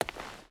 Stone Walk 2.ogg